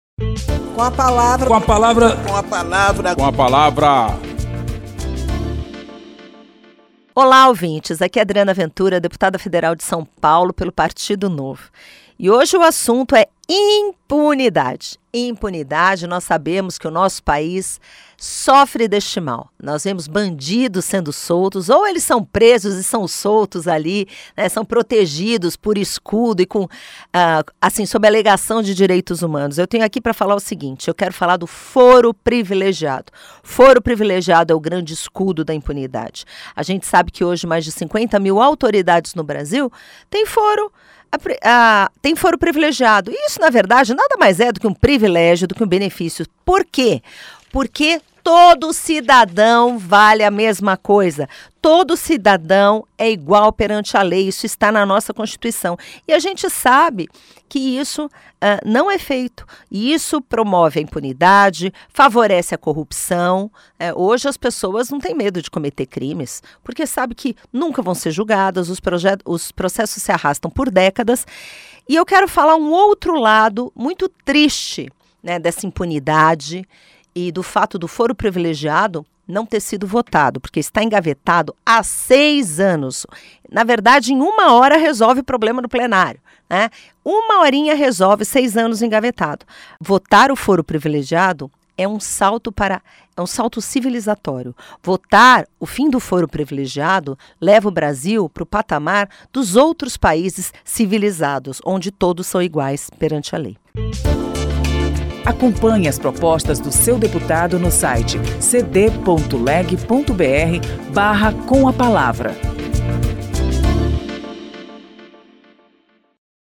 A deputada Adriana Ventura (Novo-SP) defende a votação da Proposta de Emenda à Constituição (PEC) que acaba com a prerrogativa de foro privilegiado no país.
Espaço aberto para que cada parlamentar apresente aos ouvintes suas propostas legislativas